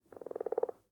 sounds / mob / frog / idle6.ogg